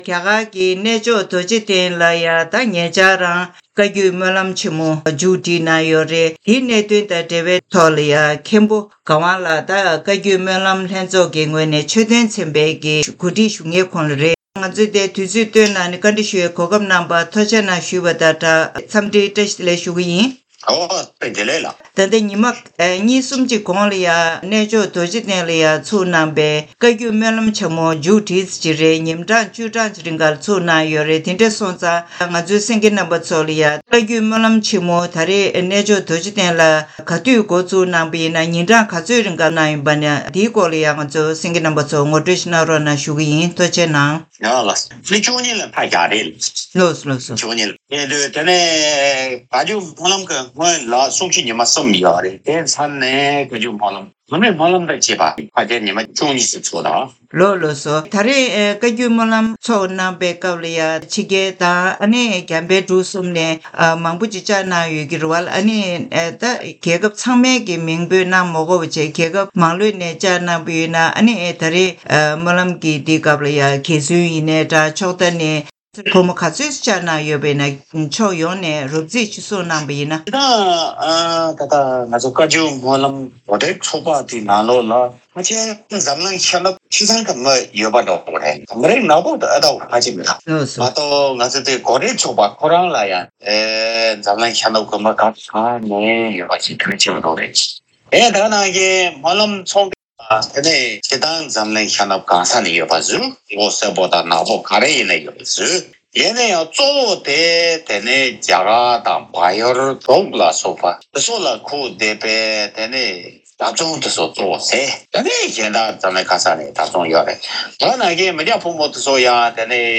གནས་འདྲི་ཞུས་པ་ཞིག་གསན་གནང་གི་རེད།